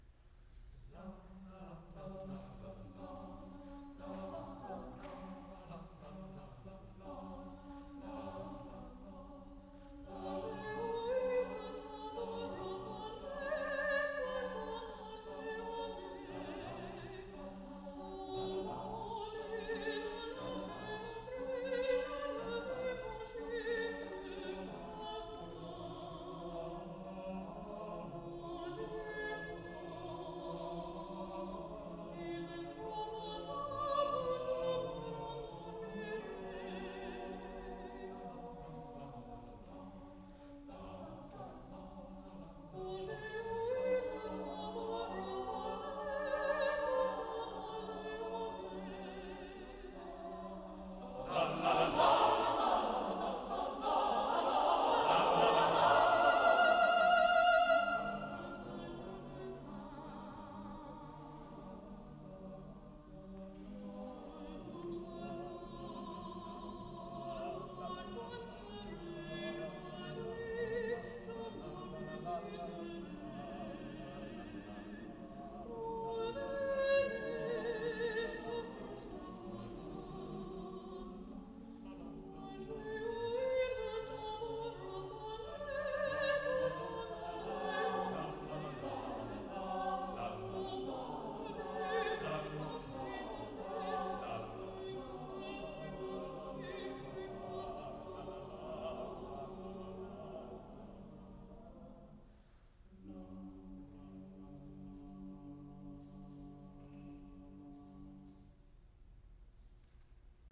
csucs - Music from the CSU Chamber Singers while I was a member
main csucs / NCCO Inaugural Conference Closing Concer / 11 Trois Chansons - II.